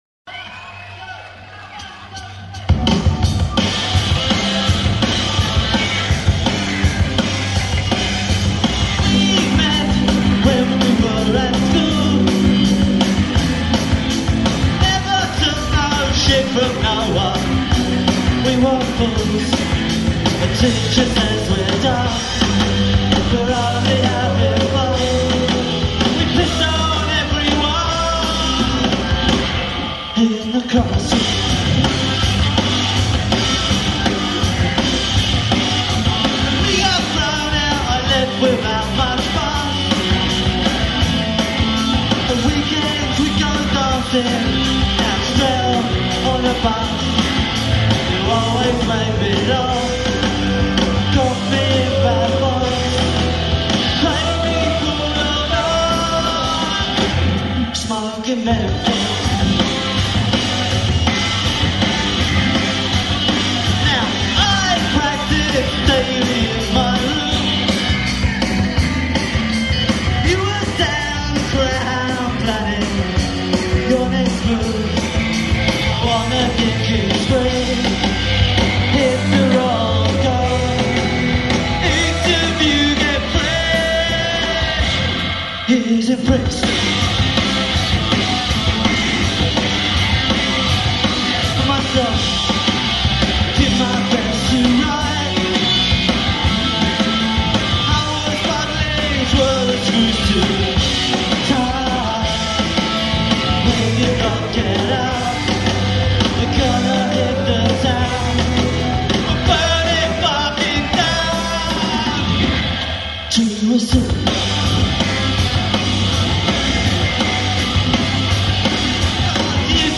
Hammersmith Punk